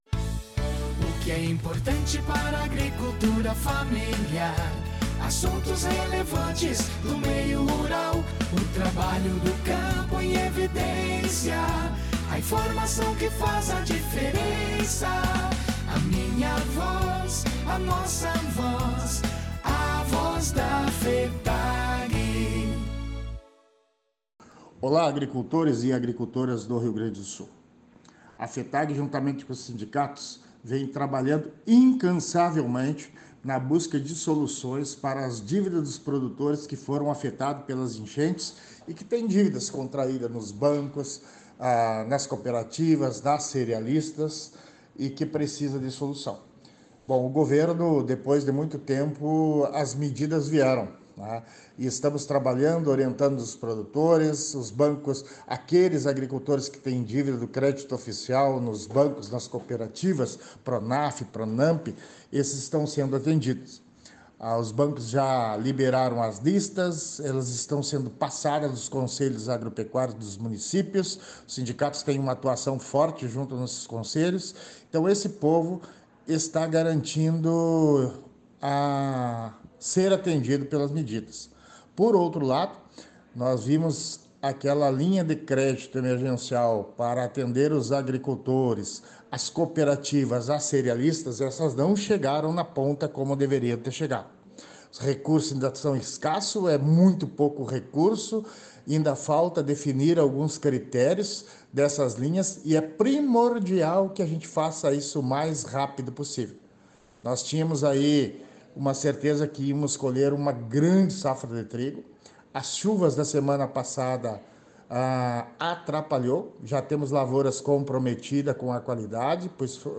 Programa de Rádio A Voz da FETAG-RS
Nova-Voz-da-Fetag-Instrumental-com-encerramento17.10.mp3